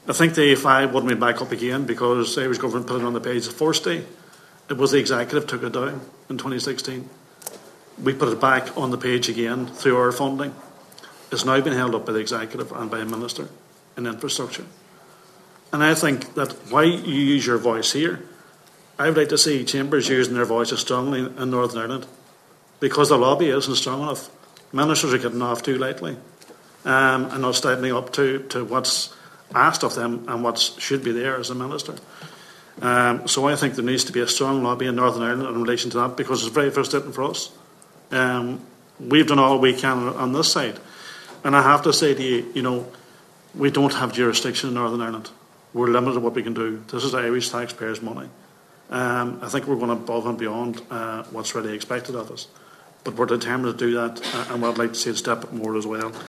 Senator Niall Blaney was speaking at the Joint Oireachtas Committee on the implementation of the Good Friday Agreement this afternoon, attended by Chambers of Commerce from Letterkenny and Derry, and says the Irish Government has done all it can: